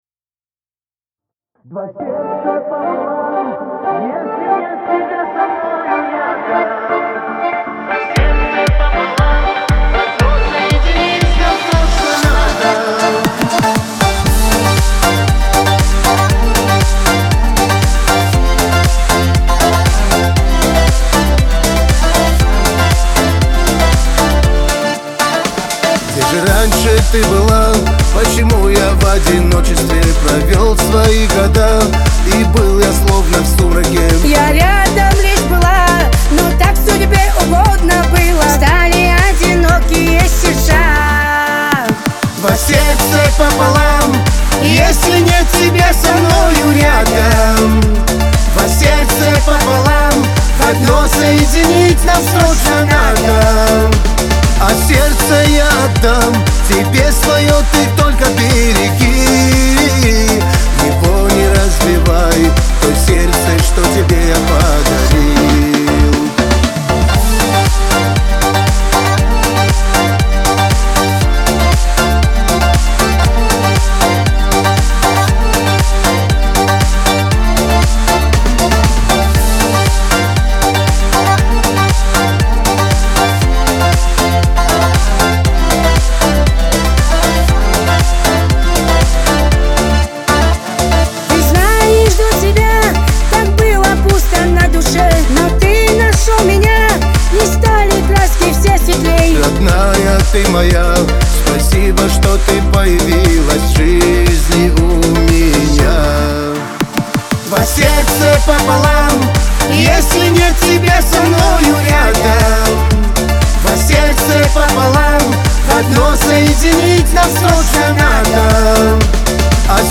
дуэт
Лирика
Кавказ – поп